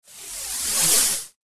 جلوه های صوتی
دانلود صدای ربات 55 از ساعد نیوز با لینک مستقیم و کیفیت بالا